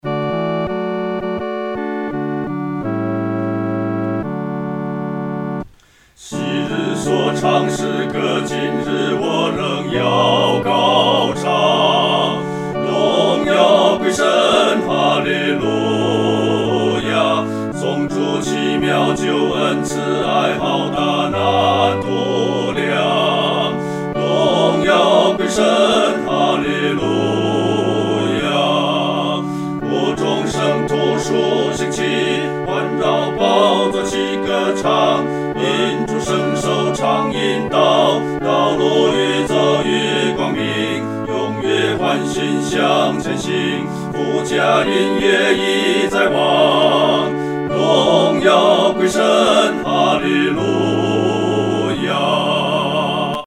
合唱（四声部）
这首赞美诗的曲调欢快，有进行曲的风味。
荣耀归神-合唱（四声部）.mp3